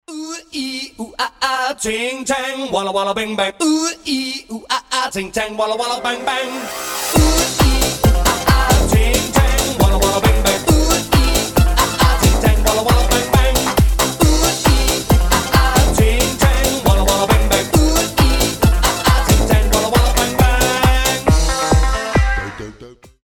• Качество: 320, Stereo
позитивные
веселые
смешные
евродэнс